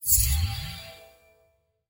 Звуки ускорения времени
Звук самой быстрой скорости из сказок